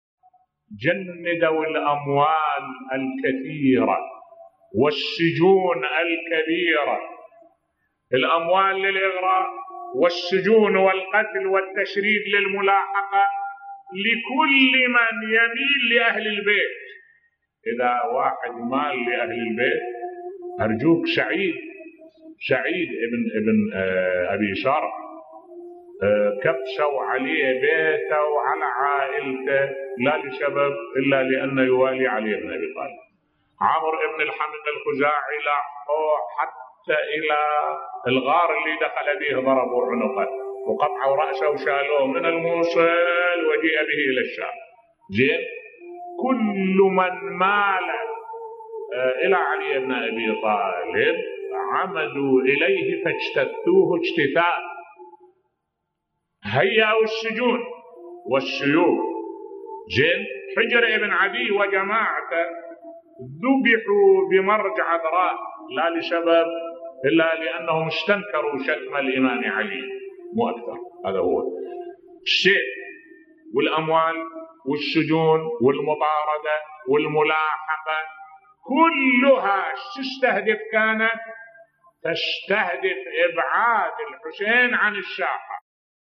ملف صوتی الشجرة الملعونة و محاربتهم لأهل البيت بصوت الشيخ الدكتور أحمد الوائلي